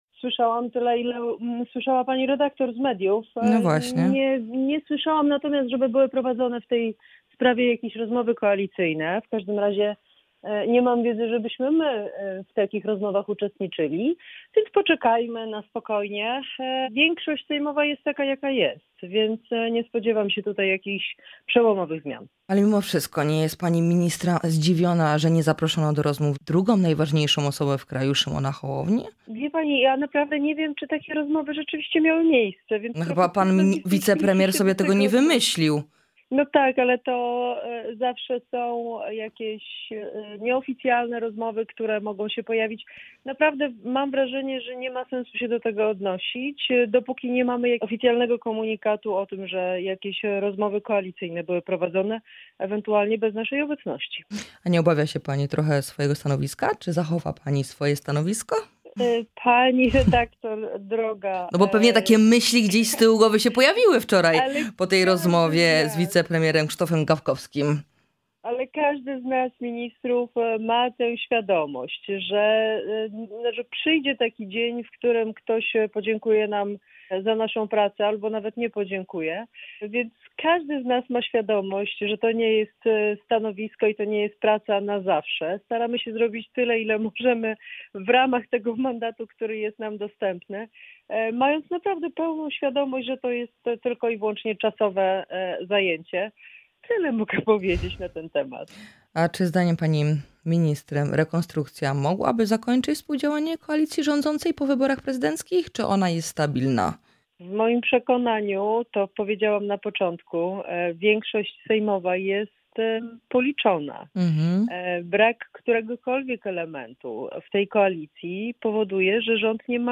Wiceminister Joanna Mucha w audycji „Poranny Gość” zapowiedziała start kampanii Szymona Hołowni.